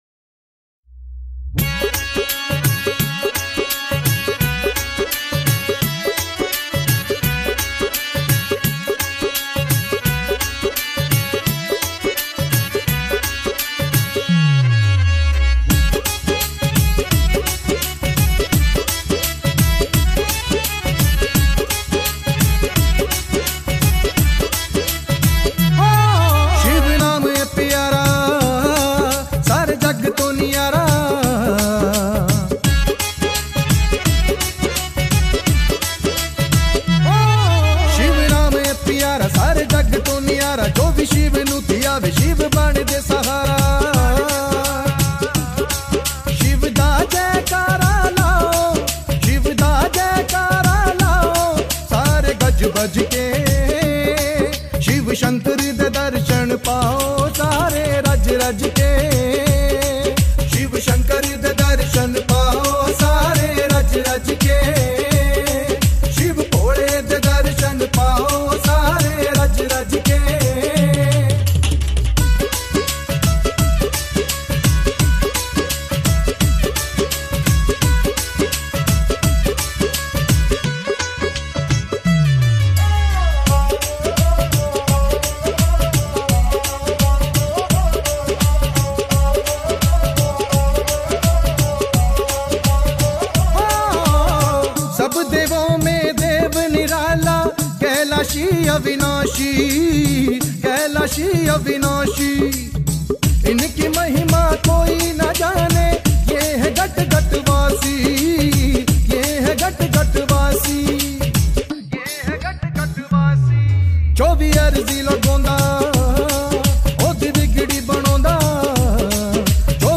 Bhakti Songs